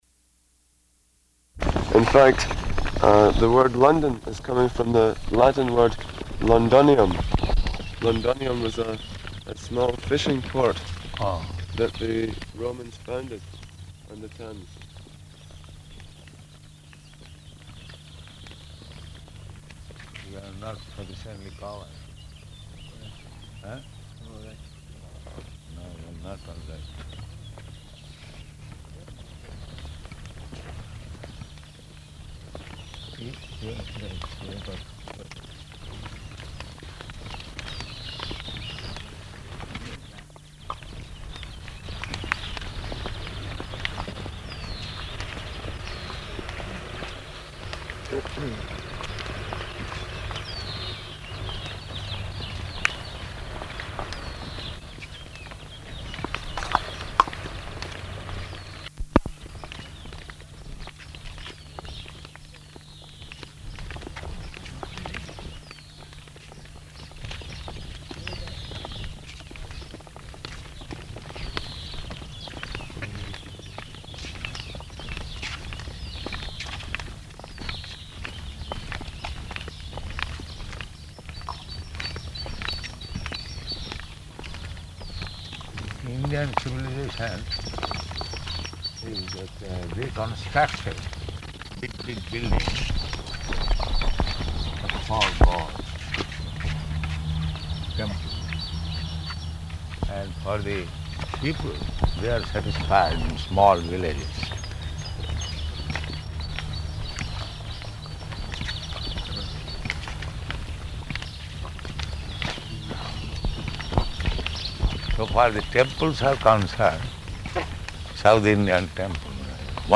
-- Type: Walk Dated: May 24th 1974 Location: Rome Audio file